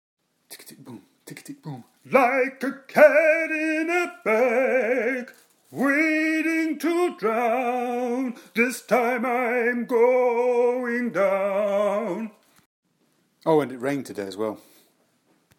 Practicing my opera singing.